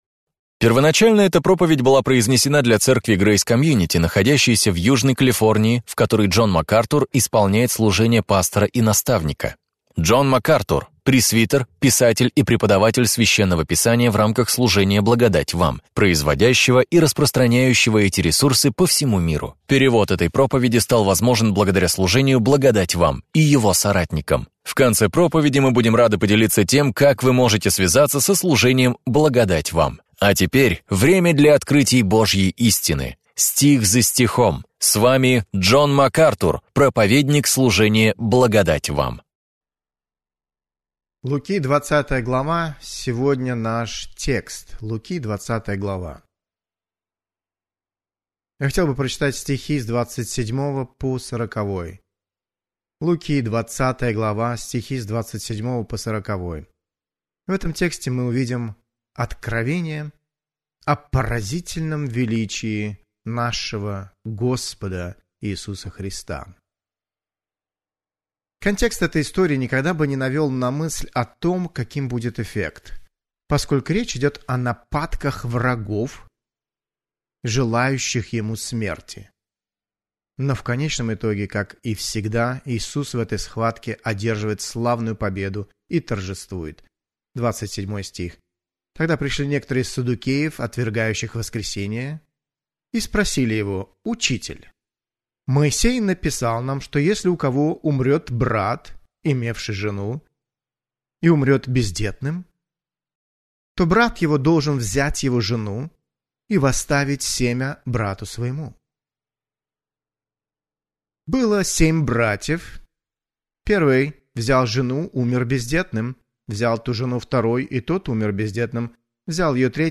Об этом и пойдет речь в проповеди Джона Макартура «Как общаться с еретиком». Вы увидите изнанку одного из острых конфликтов Христа с набожными врагами истины и Евангелия.